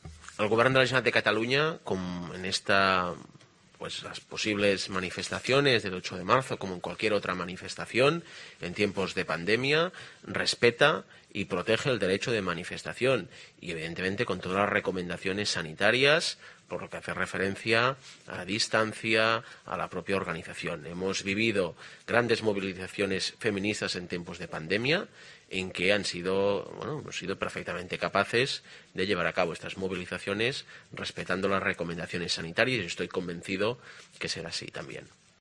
Pere Aragonés vicepresidente en funciones confirma que el Govern autorizará las manifestaciones del 8-M